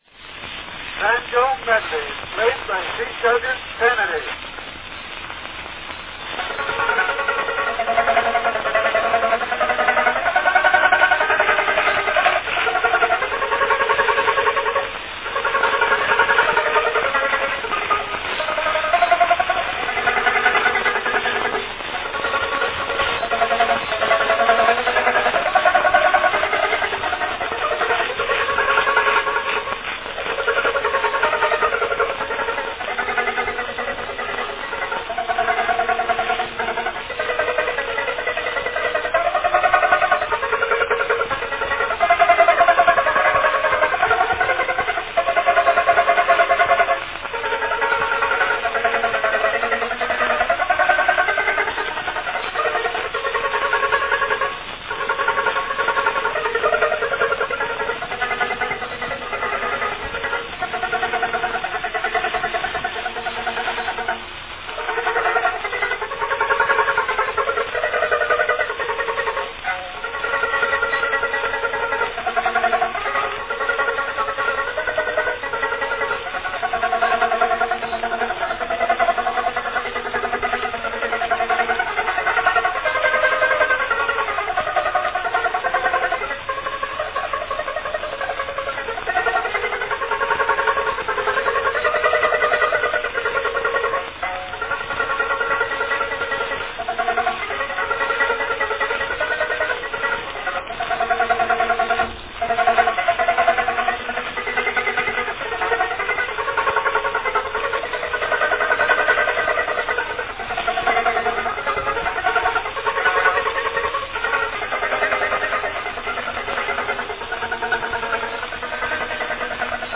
A fine homemade recording from 1906
banjo medley
Category Banjo solo
performs an inventive banjo medley
h-banjomedley~.mp3